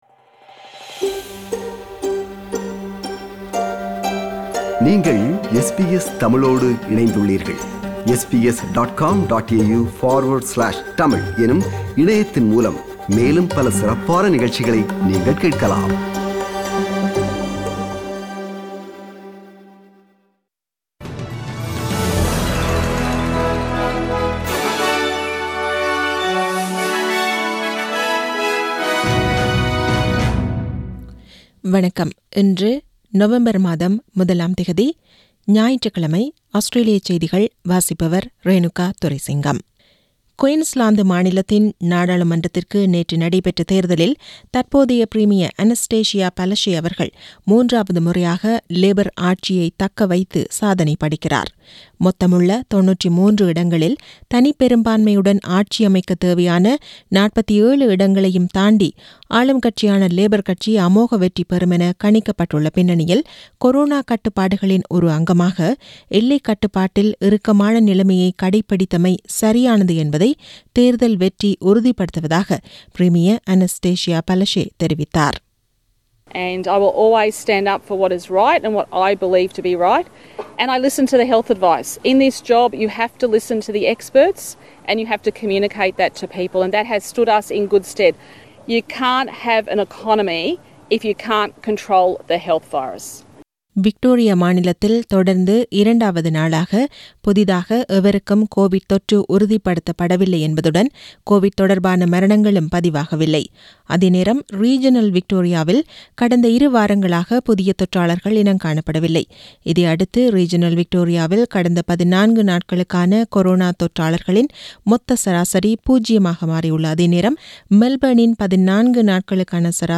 Australian news bulletin for Sunday 01 November 2020.